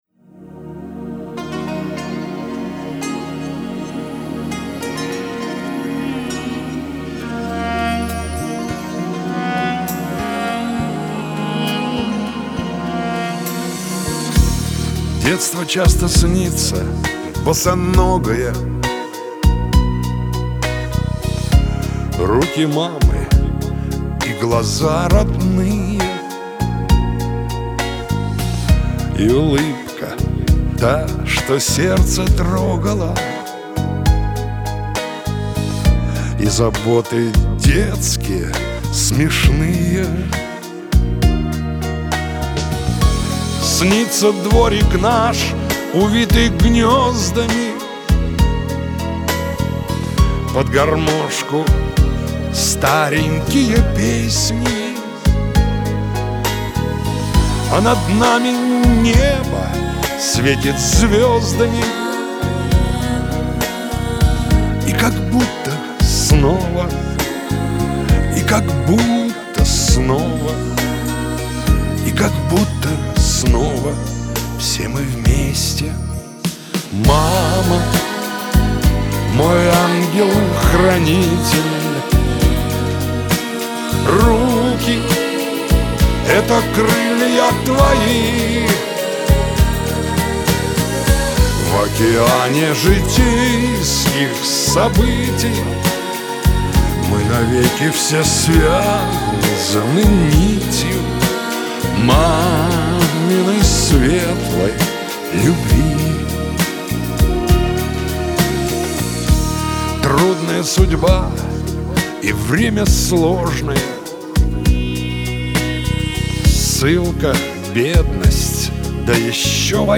второй это оригинал самого исполнителя.